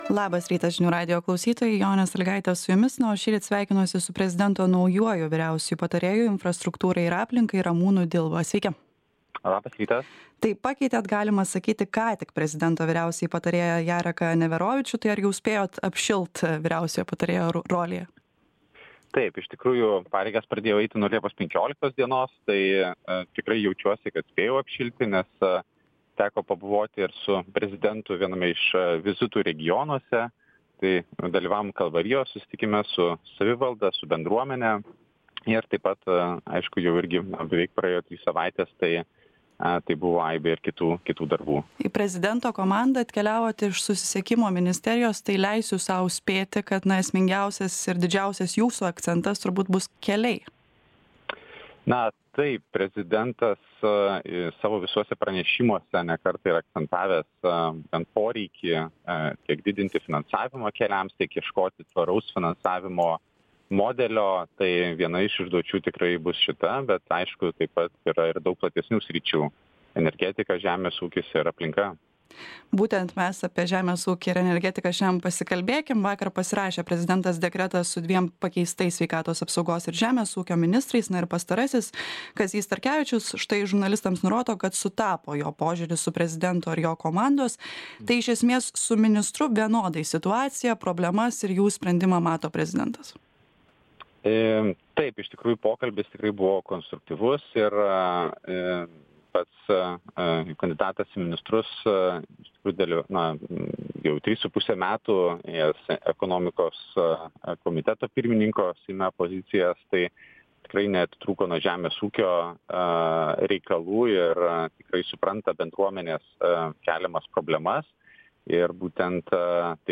Pokalbis su prezidento vyriausiuoju patarėju Ramūnu Dilba.